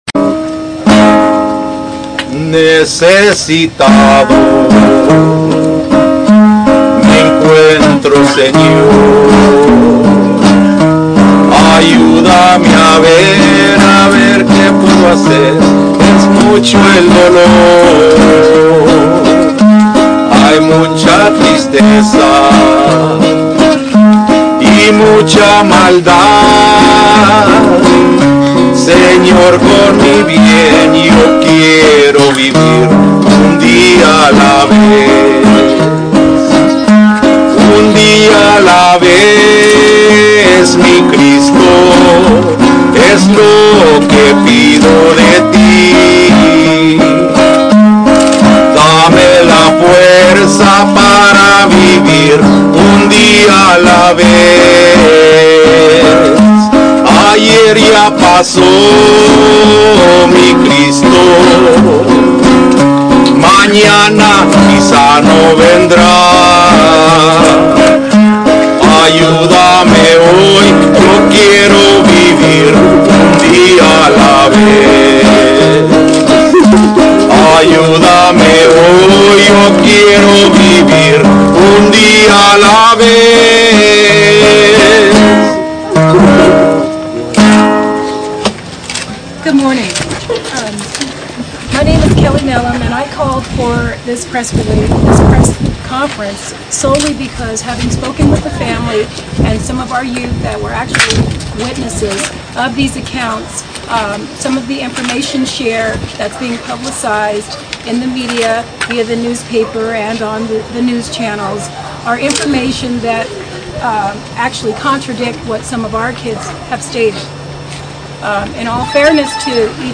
press conference